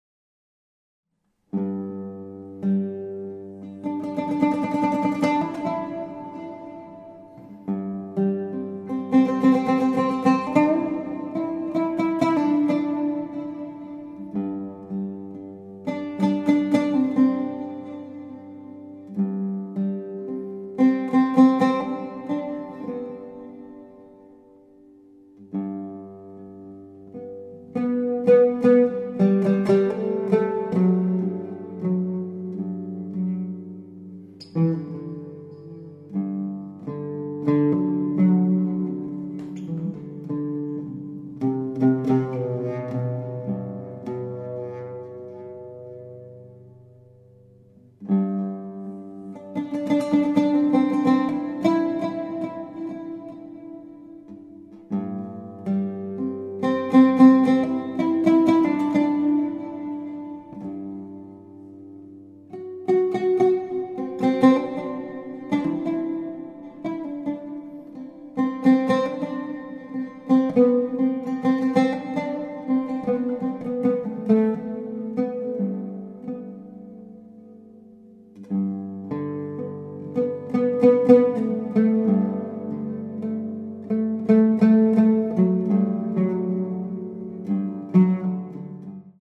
oud